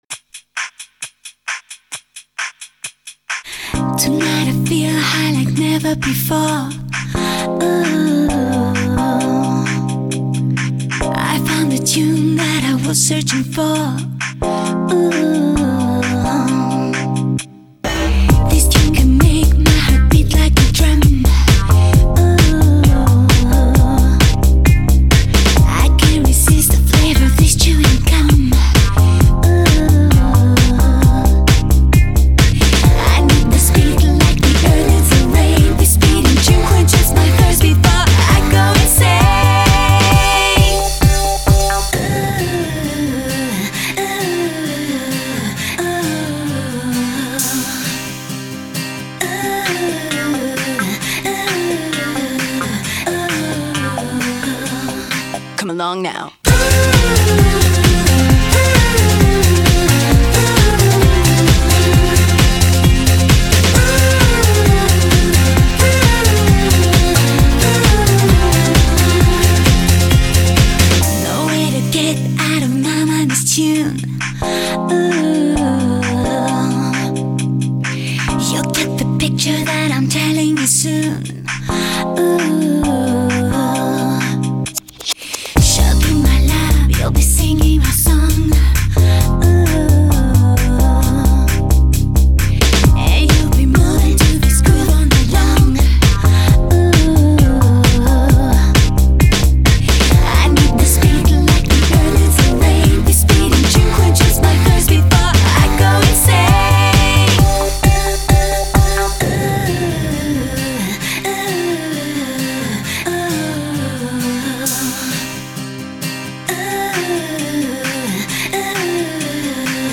взбодряющая песенка)